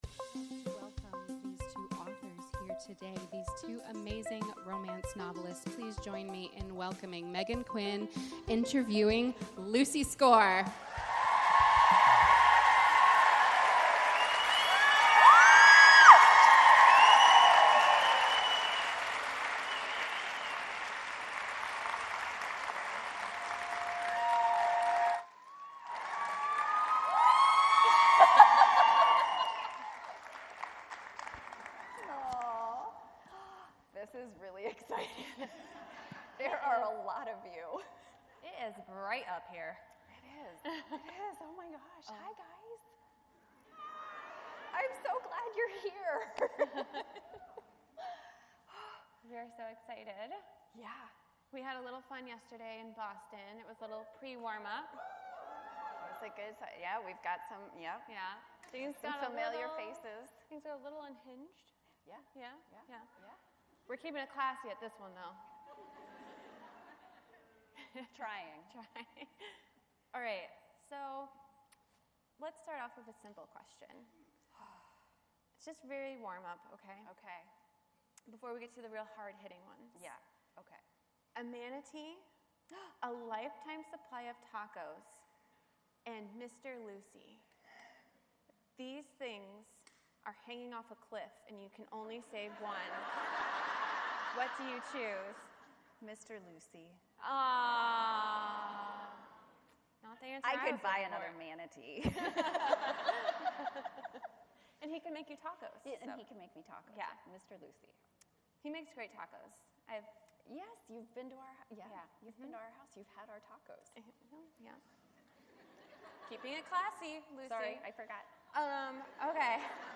Lucy Score & Meghan Quinn LIVE at the Capitol Center for the Arts on Sunday, March 16th, 2025.
Last month we hosted Romance Author Lucy Score at the Capitol Center for the Arts in conversation with Meghan Quinn, for a sold-out audience! That conversation was recorded, and now we're uploading it here for your listening pleasure.